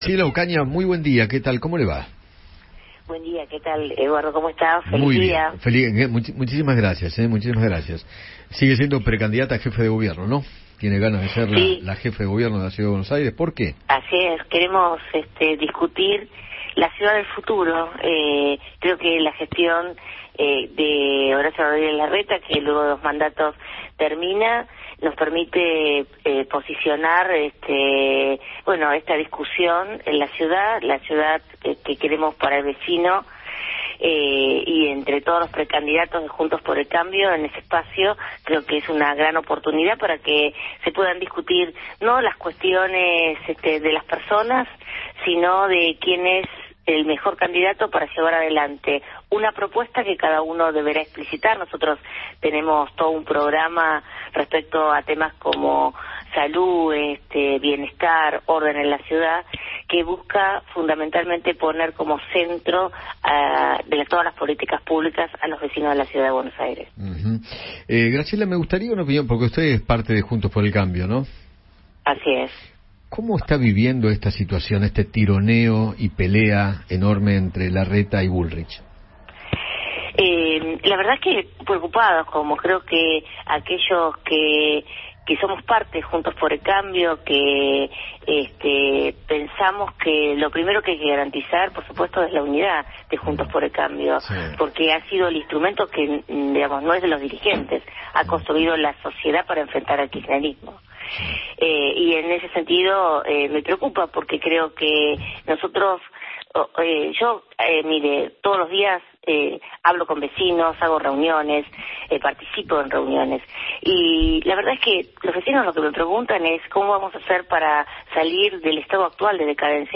Graciela Ocaña, precandidata a jefe de Gobierno porteño, habló con Eduardo Feinmann sobre la interna de Juntos por el Cambio de cara a las elecciones 2023.